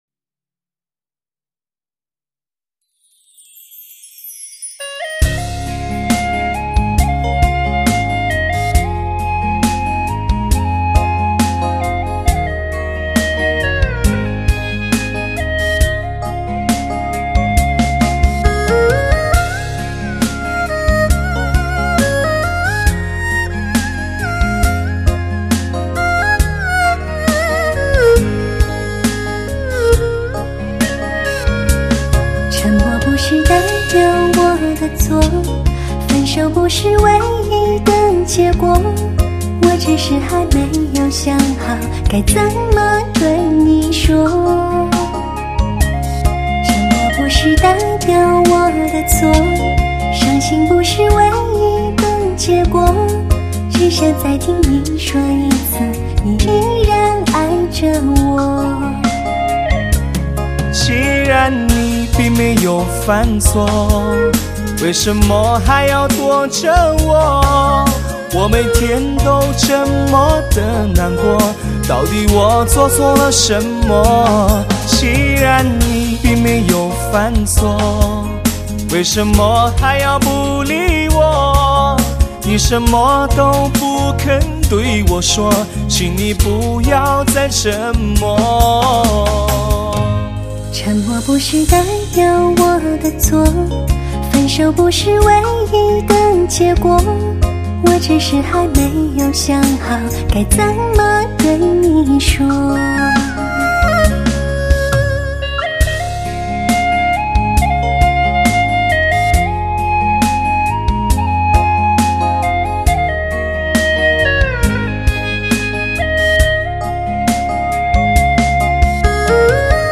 经典男声+经典女声
HIFI倾情对唱，柔润，丝滑，抚慰心灵经典情歌。